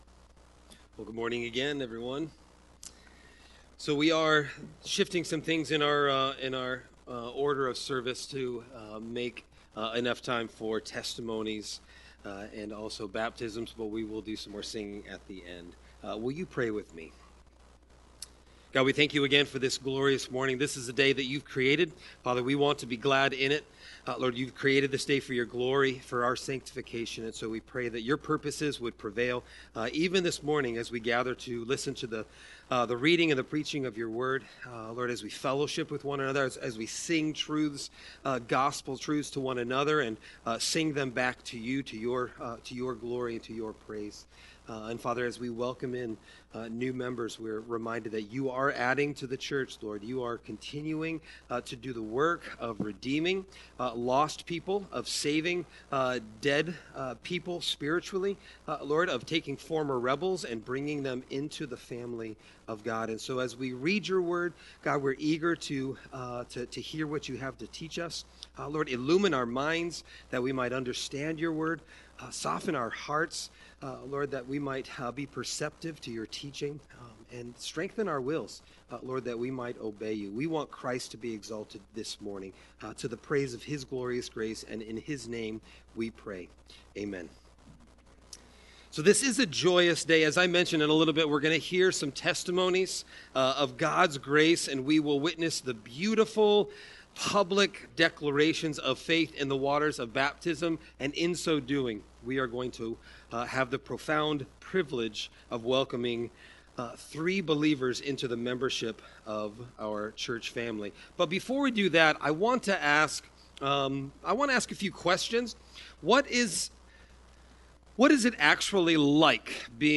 Sermon Text: 1 Thessalonians 5:14-15